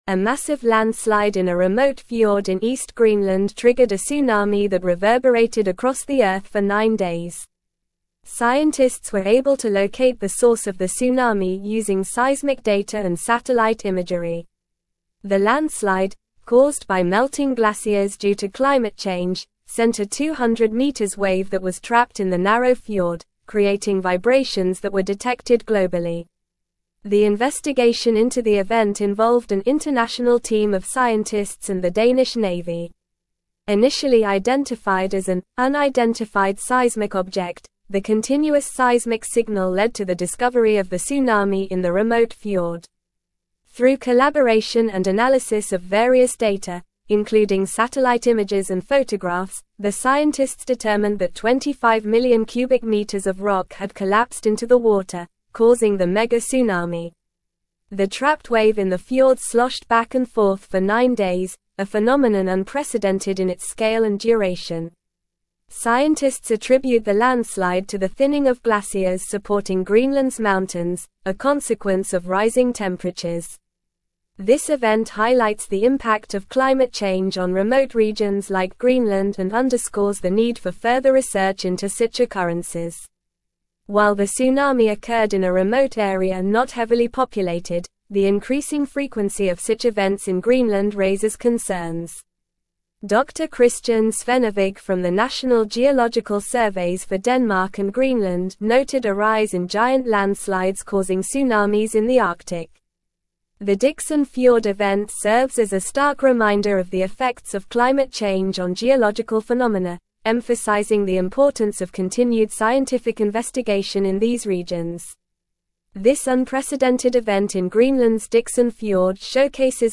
Normal
English-Newsroom-Advanced-NORMAL-Reading-Greenland-Tsunami-Scientists-Unravel-Cause-of-Mysterious-Tremors.mp3